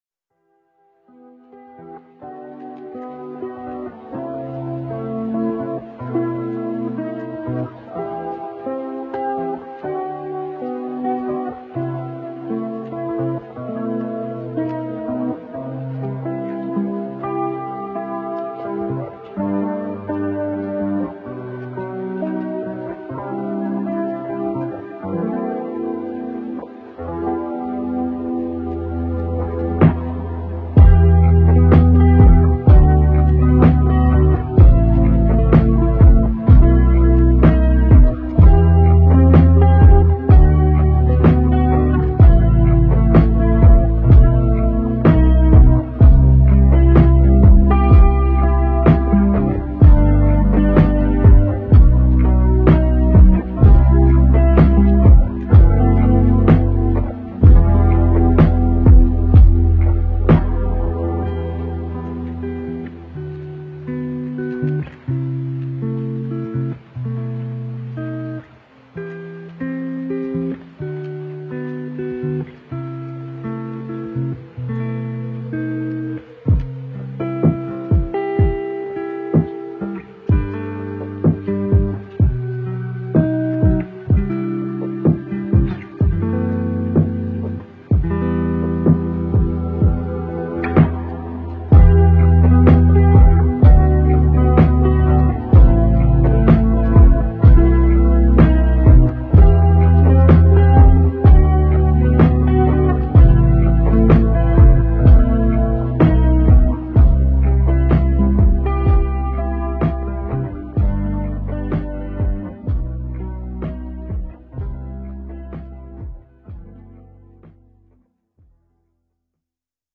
MusicLofi.mp3